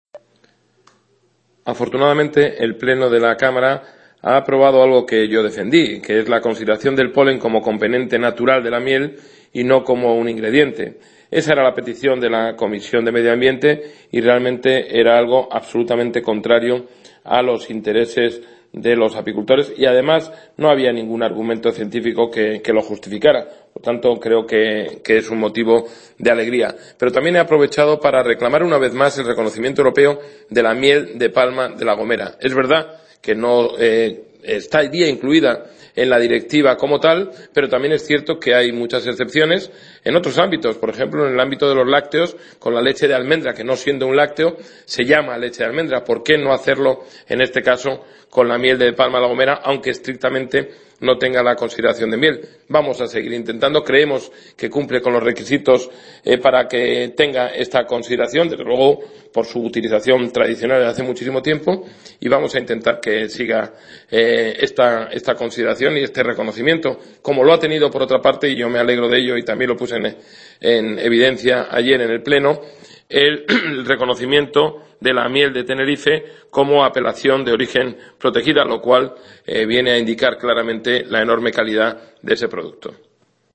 El eurodiputado canario del PP Gabriel Mato reiteró este miércoles, en el Pleno del Parlamento Europeo, la necesidad de aprobar el reconocimiento europeo de la denominación Miel de Palma de La Gomera, un producto que, explicó, “aunque no entra en la definición de miel, tendría todos los elementos para ser una excepción, dado que se conoce claramente por su utilización tradicional”.